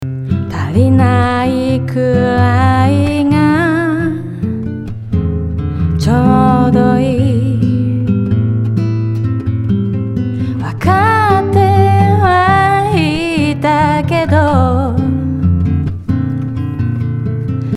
イメージとしてはあたたかな響きでありながら声に「残り香を感じさせる」ことにしました。
先ほどのプリセットよりもリバーブタイムは短めですが、こっちの方がより響きを感じさせてくれています。